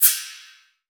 FX CYMB 1.WAV